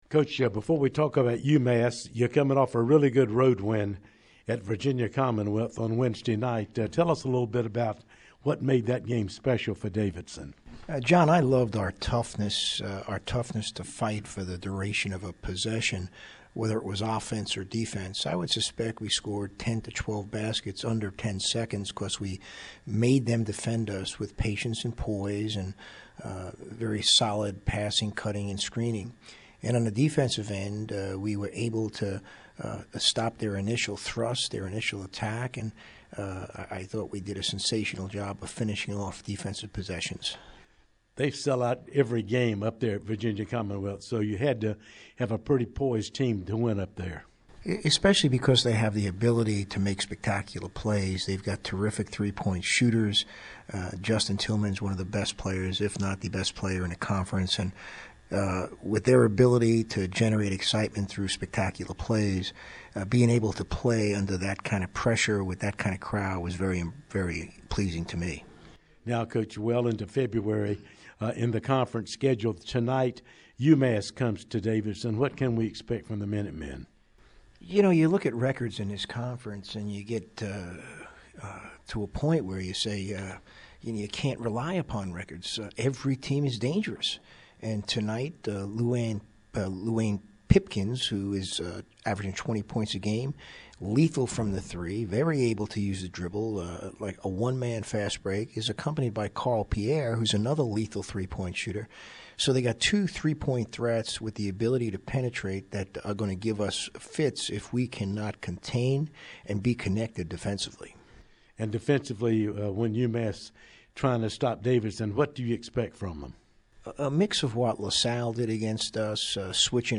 Pregame Interview
Pregame UMass.mp3